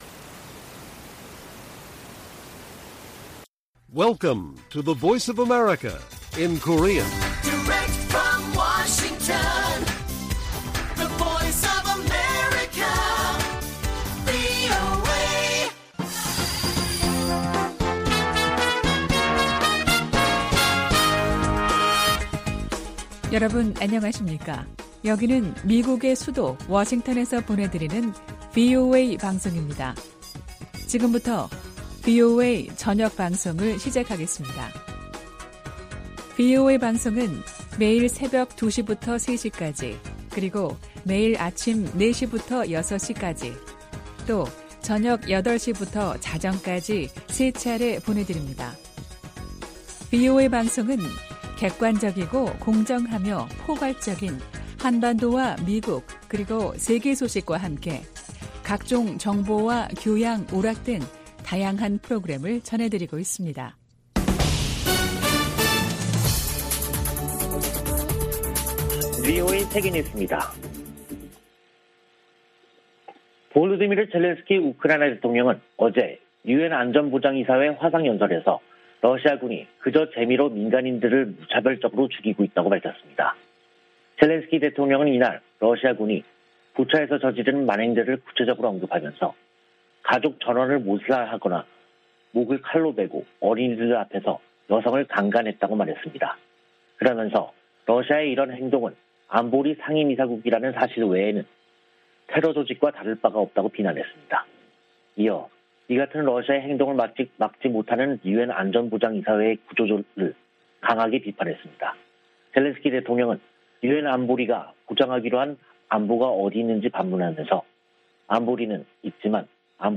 VOA 한국어 간판 뉴스 프로그램 '뉴스 투데이', 2022년 4월 6일 1부 방송입니다. 미 국무부는 탄도미사일 발사가 북한을 더욱 고립시키고 한반도 안정을 해치는 행위일 뿐이라고 지적했습니다. 북한의 탄도미사일 역량이 미 본토에까지 실질적인 위협이 되고 있다고 미 합참의장이 평가했습니다. 백악관 국가안보보좌관은 미국을 방문한 한미정책협의대표단을 면담하고 정상회담 조기 개최와 전략자산 배치 등에 대해 논의한 것으로 알려졌습니다.